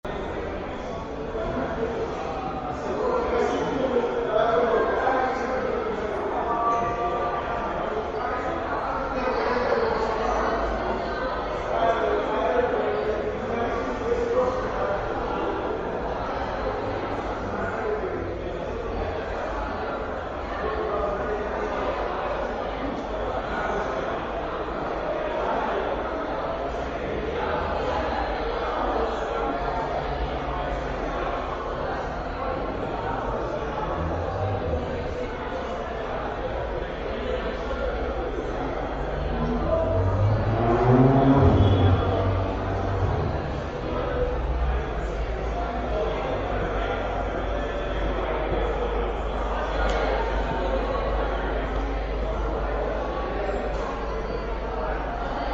conversation inside and the street outside
ambiences